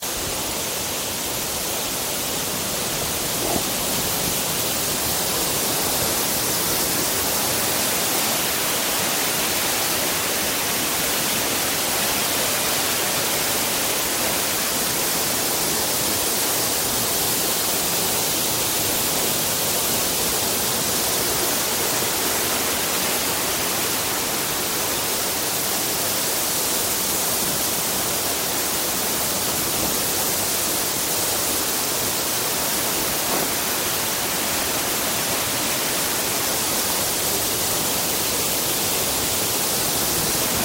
เสียงบรรยากาศ